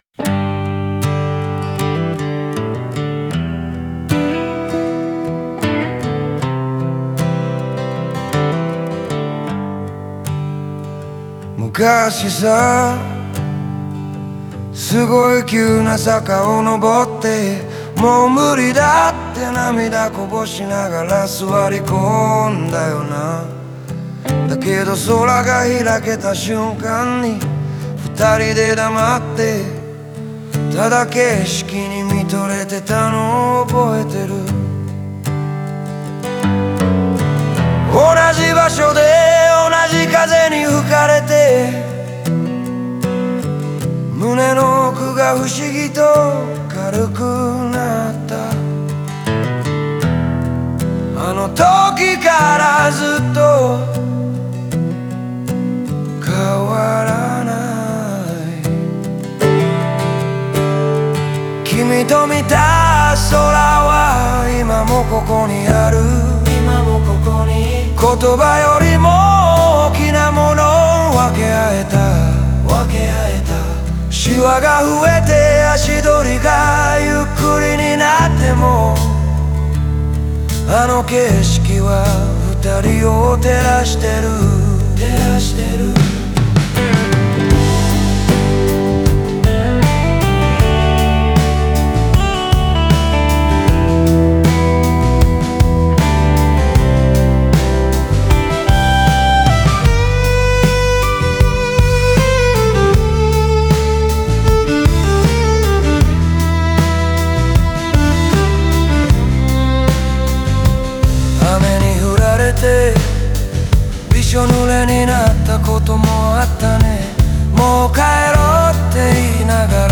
コーラスは過去と現在を重ねる響きとなり、共に見上げた空の大きさや時間の積み重ねをより強調しています。